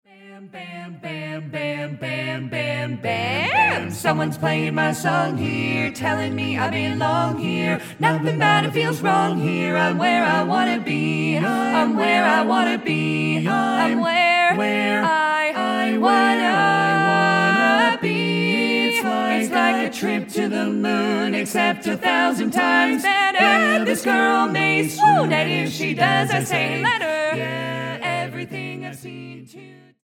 Musical Theatre